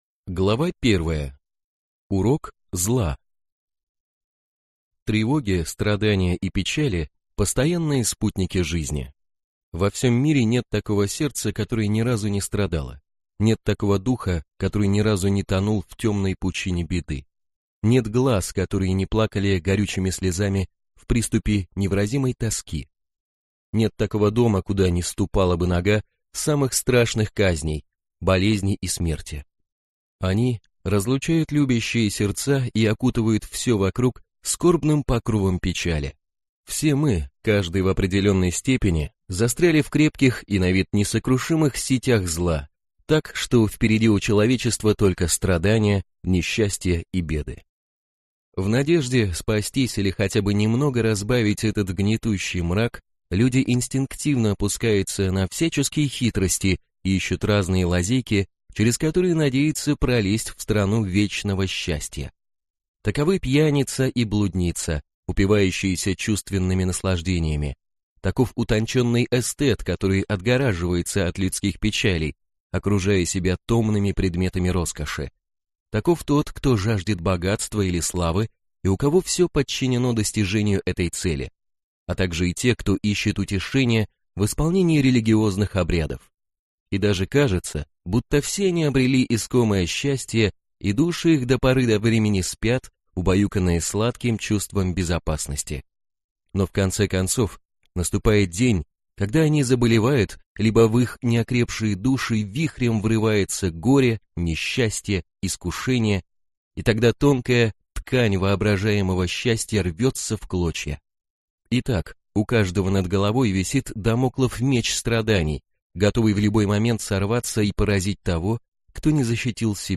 Аудиокнига Путь процветания | Библиотека аудиокниг